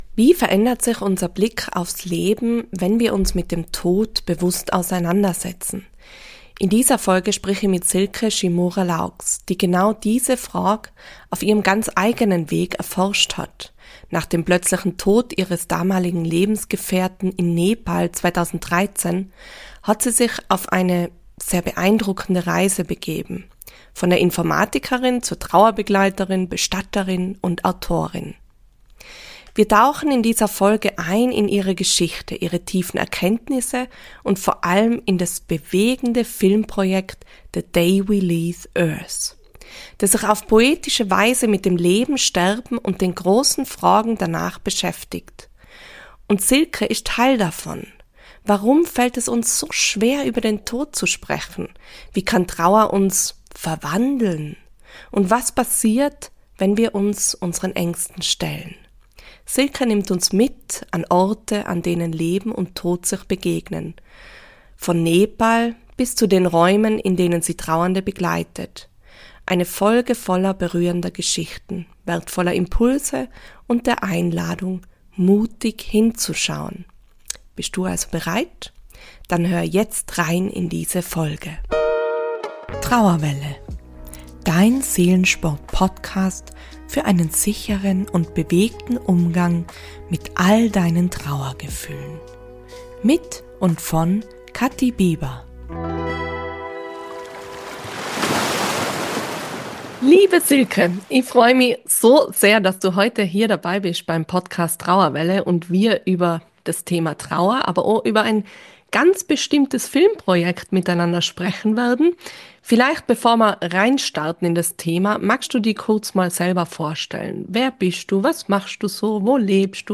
#86 Warum wir den Tod nicht fürchten müssen - Ein Gespräch